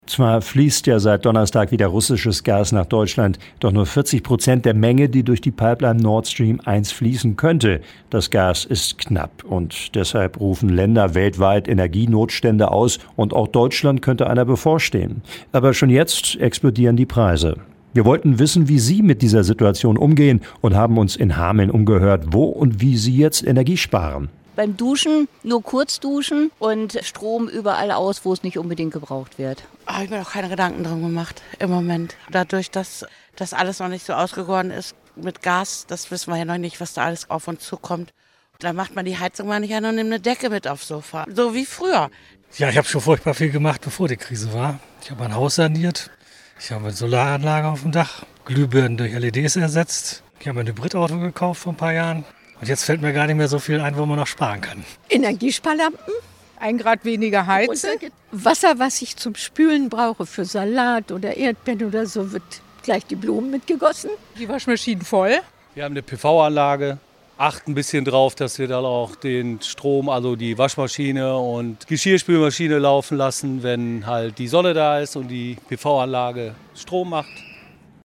Landkreis Hameln-Pyrmont: UMFRAGE ENERGIE SPAREN
landkreis-hameln-pyrmont-umfrage-energie-sparen.mp3